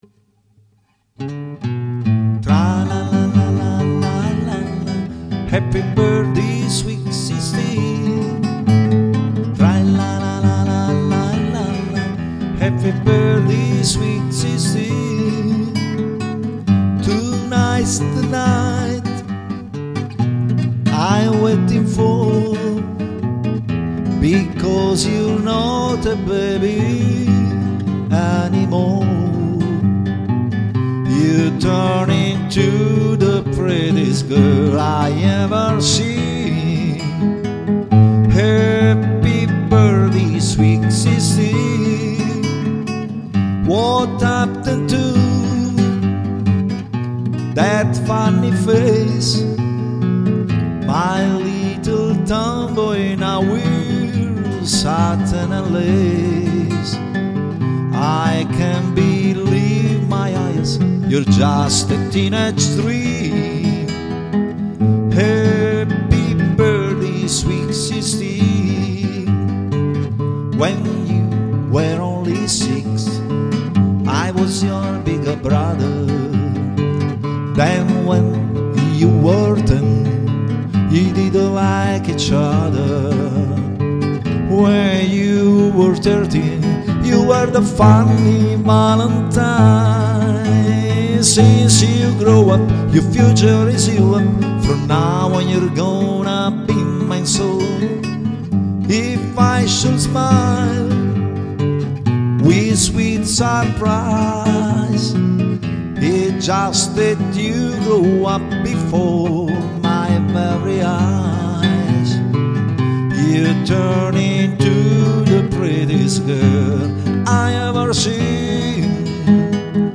Chitarra e voce